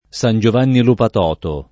San Giovanni [San Jov#nni] top.